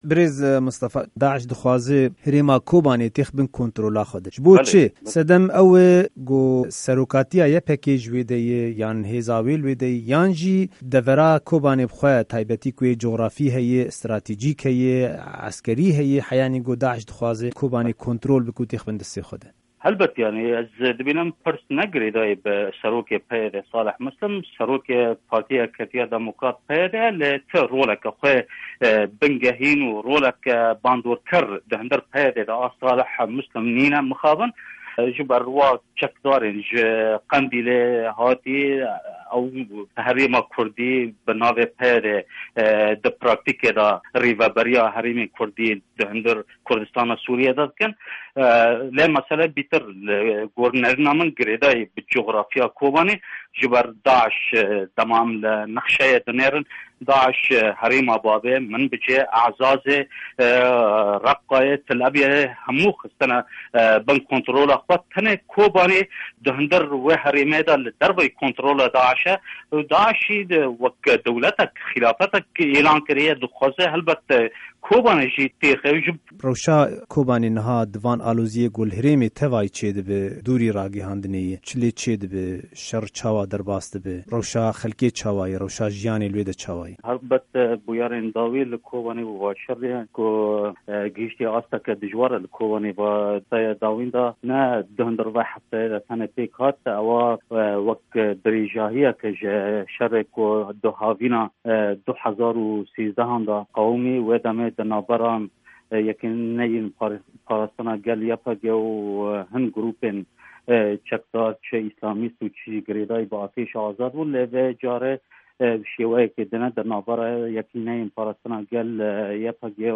Di hevpeyvîna Dengê Amerîka de parêzêr